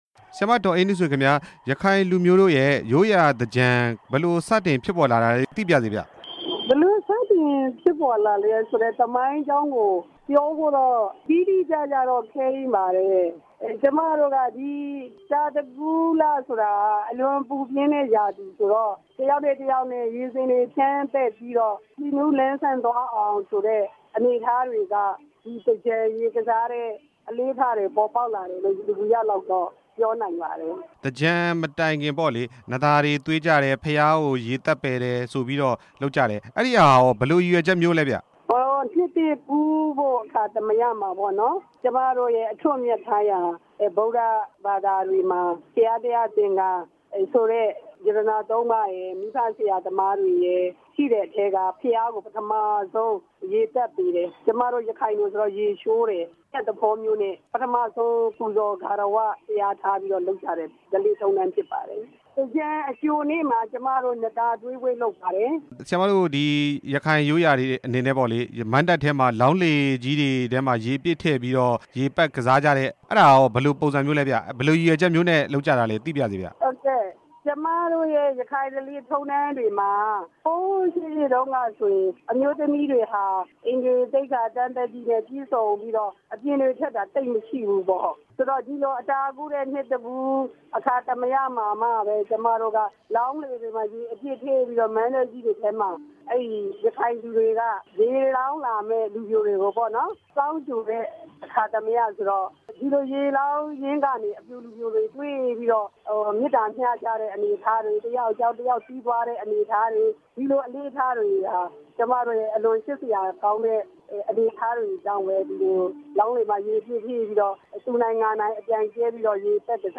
ရခိုင်ရိုးရာသြင်္ကန်ဆင်နွှဲကြပုံ အကြောင်း မေးမြန်းချက်